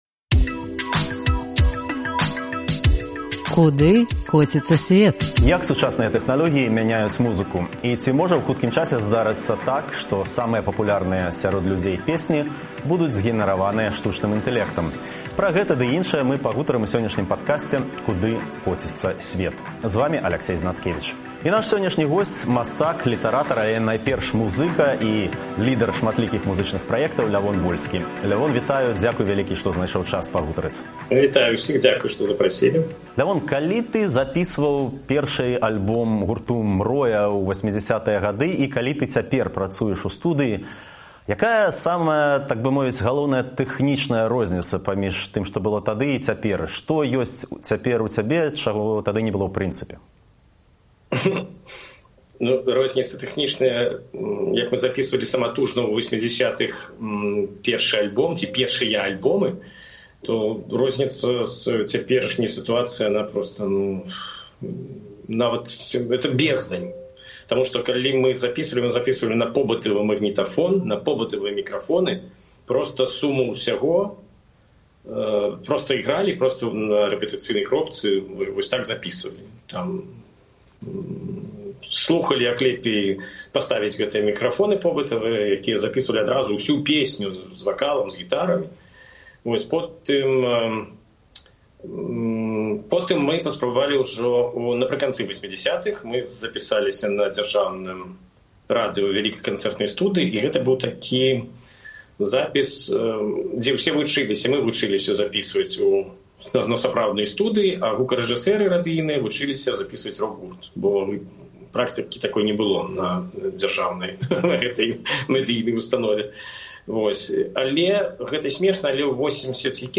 Як сучасныя тэхналёгіі мяняюць музыку? Ці можа хутка здарыцца так, што першыя месцы ў хіт-парадах будуць займаць песьні, згенэраваная штучным інтэлектам? Пра гэта ў падкасьце «Куды коціцца сьвет» -- размова зь мастаком і лідэрам шматлікіх музычных праектаў Лявонам Вольскім.